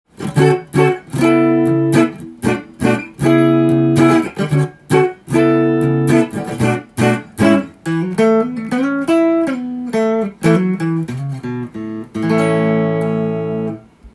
最近は、見かけることが少なくなったセミアコと言われるタイプの
試しに弾かせて頂きました
生音がすでに大きかったので鳴りが抜群です。
音の方は、枯れた感じがする渋い音でした。
やはりアメリカで作られているのでカラッとしたギターサウンド。
フロントピックアップマイクで
コードを弾くと箱鳴り感が半端ないですね！ラリーカールトンみたいな音がしました。